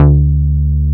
R MOOG D3F.wav